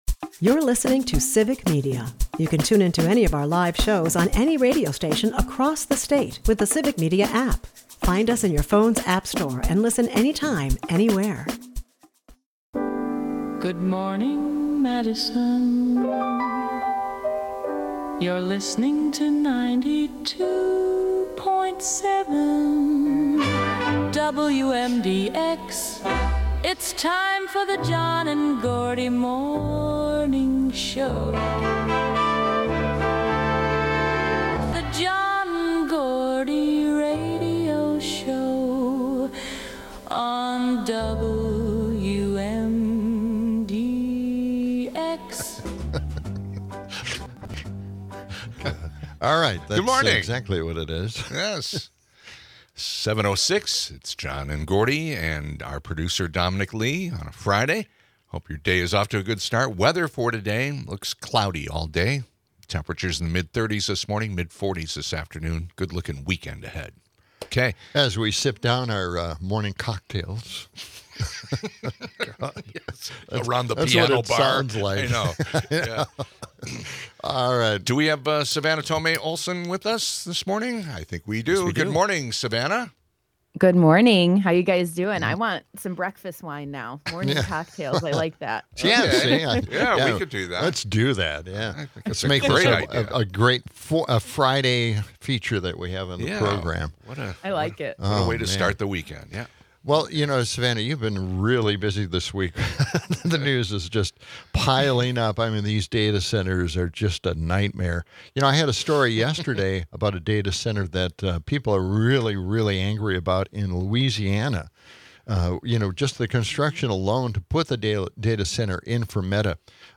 The show wraps with lively local music updates and Thanksgiving turkey shopping tips, served with a side of humor and community spirit.